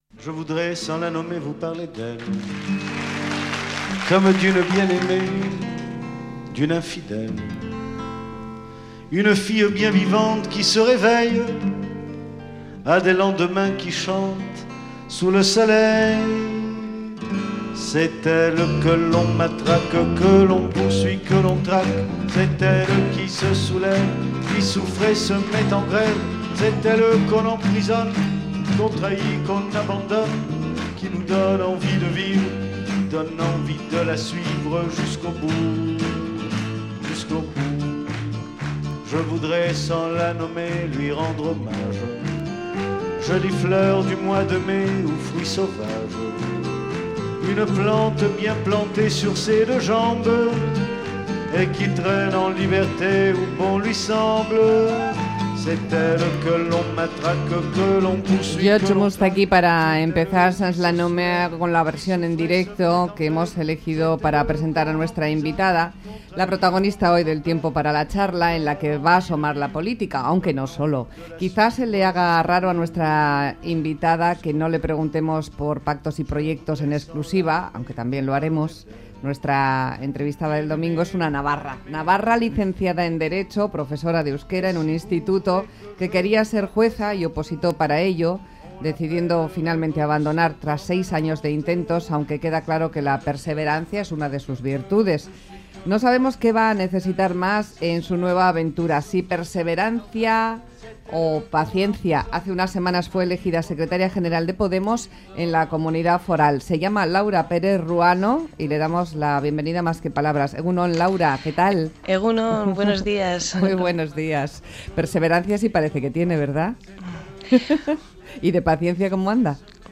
Entrevista personal